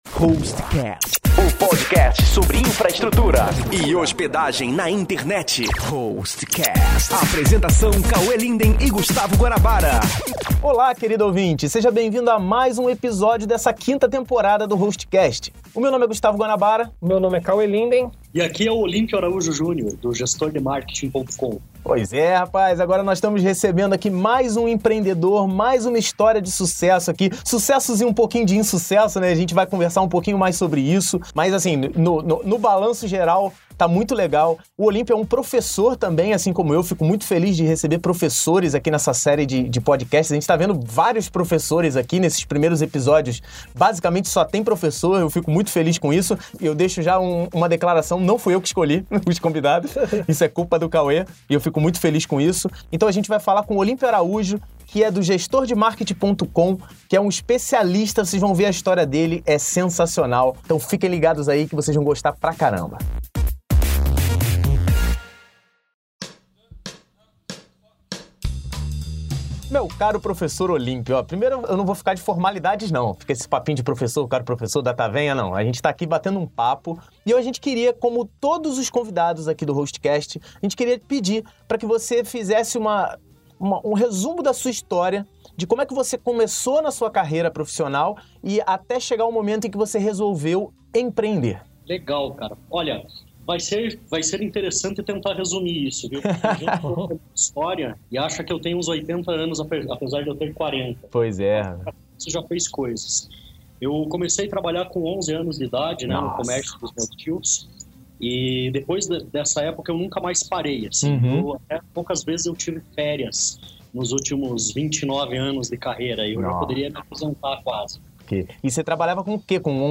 A 5ª temporada do Hostcast, o Podcast sobre Infraestrutura e Hospedagem de Sites da Hostnet, é dedicada ao tema Empreendedorismo Digital e traz entrevistas com profissionais que se destacaram na Internet.